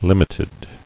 [lim·it·ed]
lim"it*ed*ness, n. The quality of being limited.